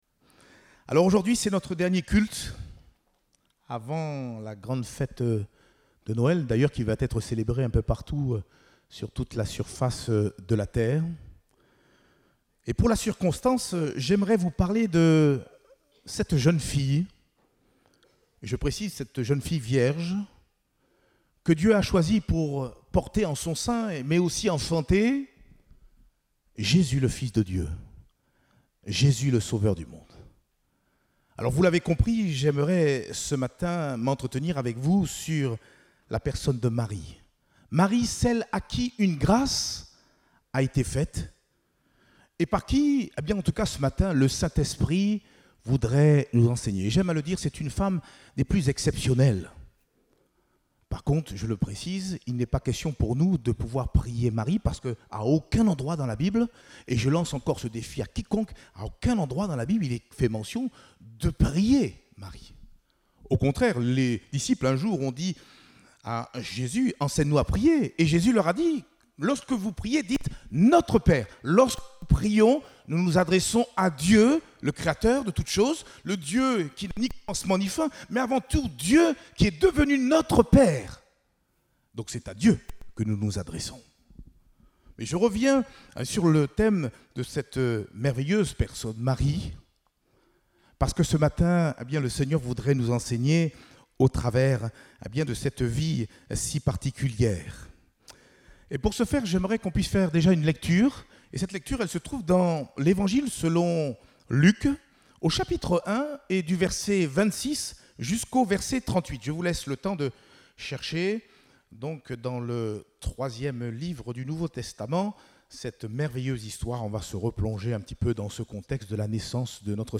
Date : 19 décembre 2021 (Culte Dominical)